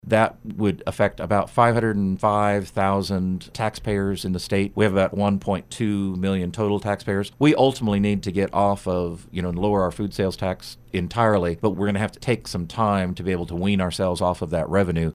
Kansas Lt. Gov. Lynn Rogers stopped by KMAN this morning to discuss various items proposed in Gov. Laura Kelly’s State of the State address and 2020 budget proposal.